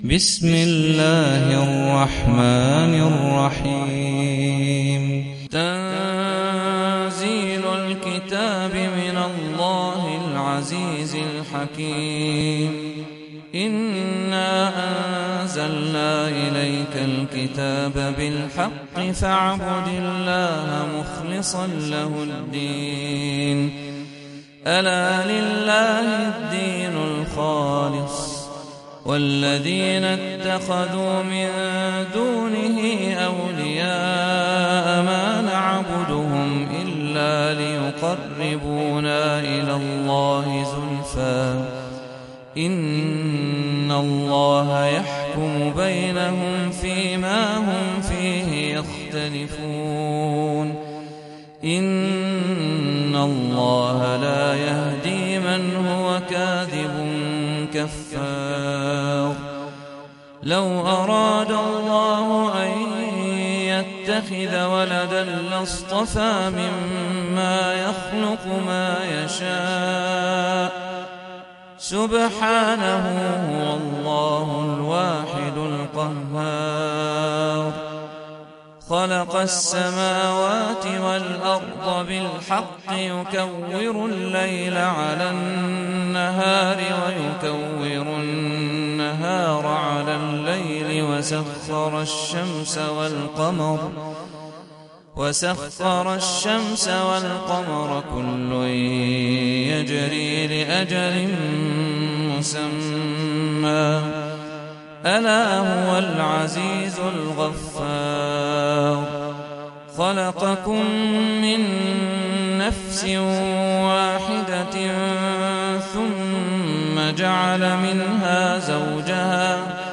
Sûrat Az-Zumar (The Groups) - صلاة التراويح 1446 هـ (Narrated by Hafs from 'Aasem